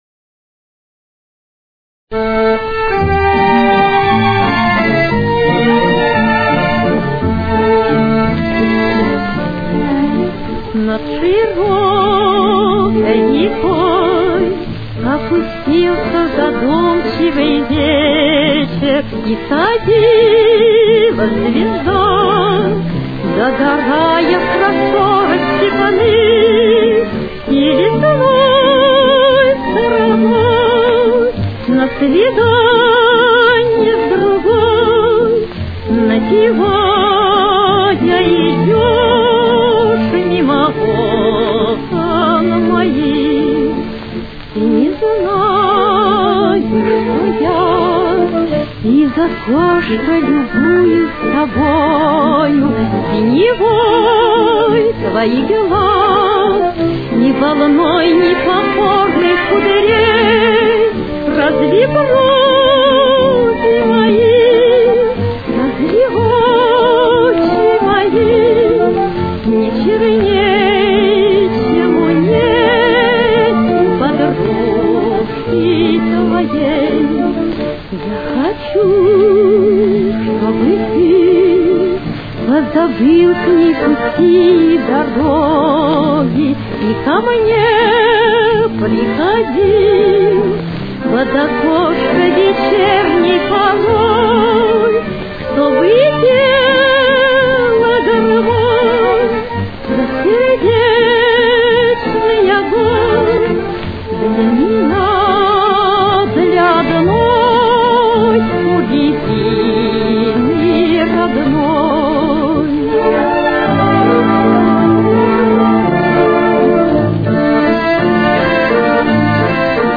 Темп: 173.